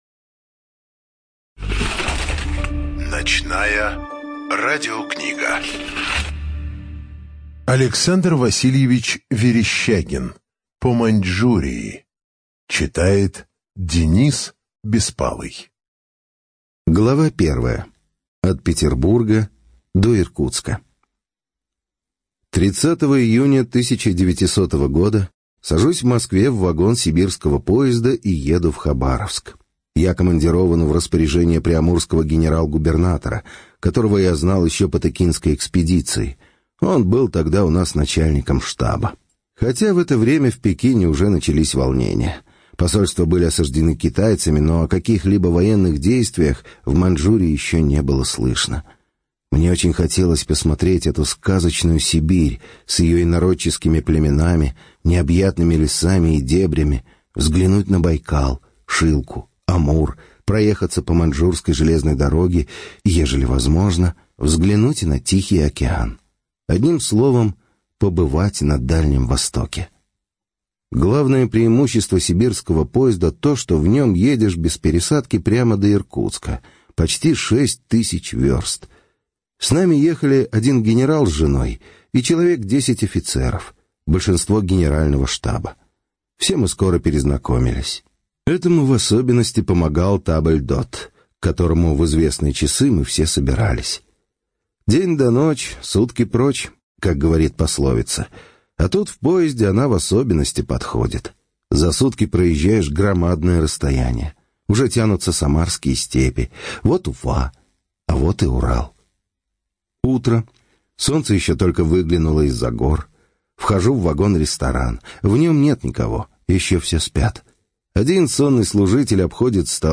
Студия звукозаписиТРК "Звезда"